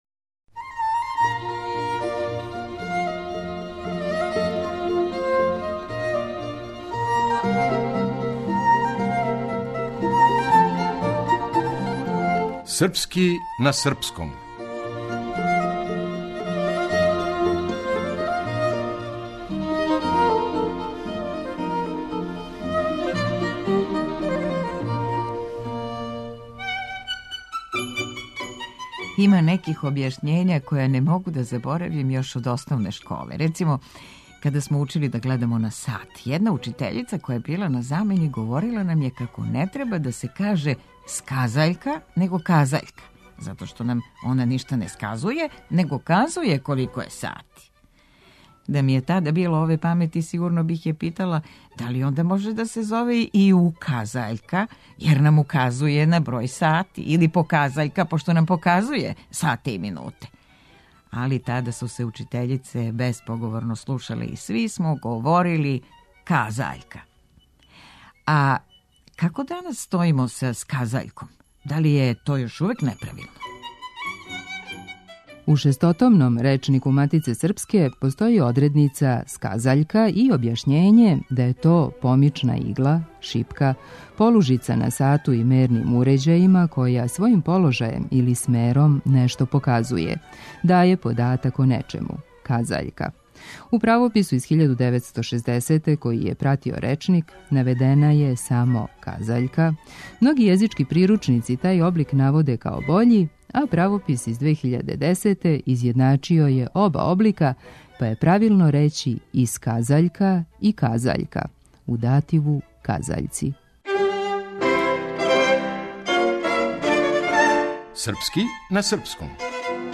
Драмска уметница